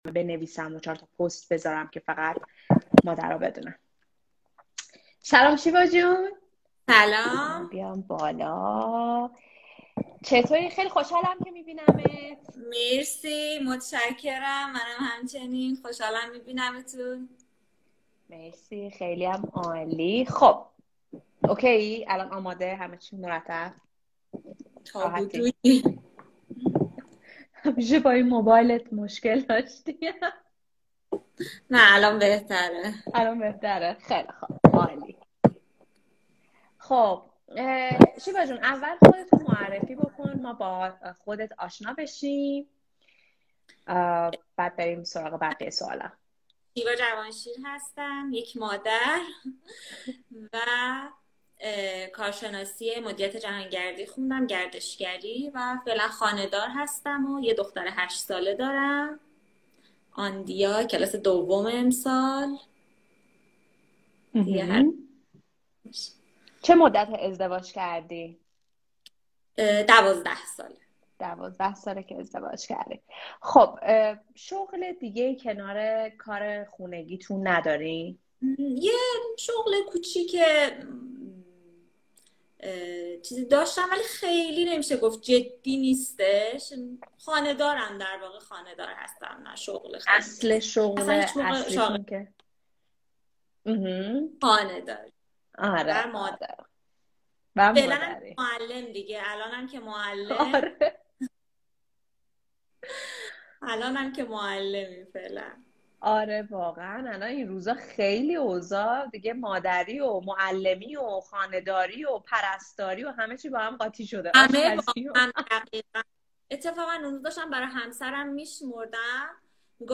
مصاحبه با مادر بالنده 2